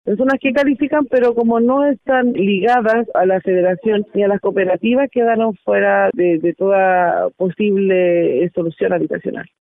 Sin embargo, no todas las familias califican para postular al proyecto habitacional que pretende levantar el Gobierno en las hectáreas expropiadas. Así lo manifestó de manera anónima una vecina de los sectores a desalojar.